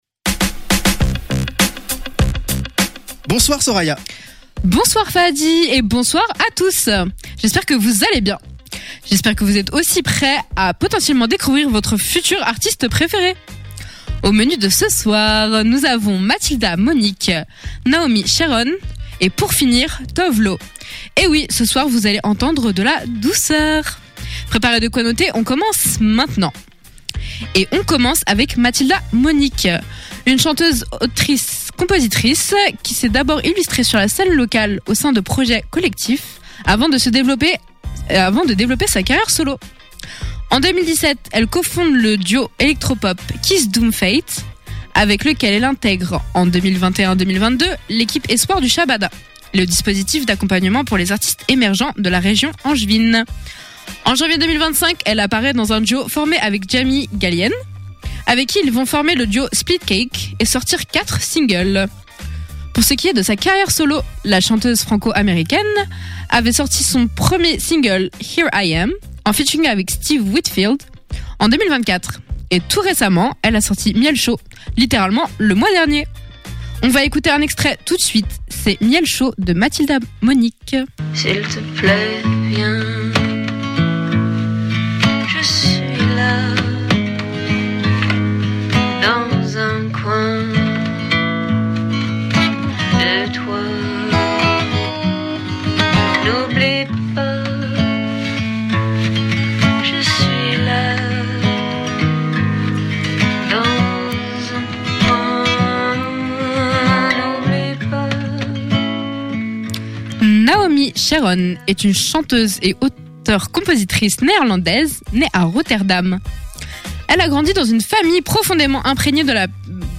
etoui ce soir vous allez entendre de la douceur.